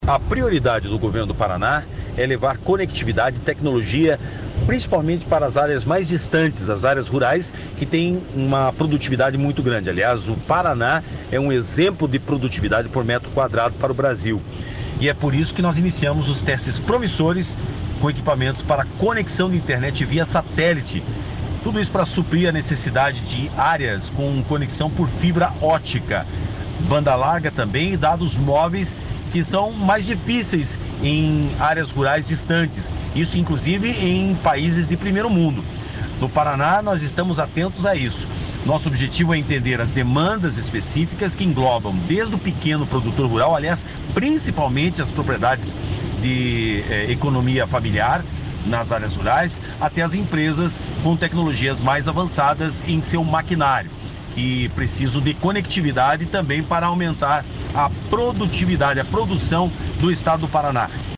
Sonora do secretário Estadual da Inovação, Modernização e Transformação Digital, Marcelo Rangel, sobre os testes para reforçar conexão de internet via satélite em áreas rurais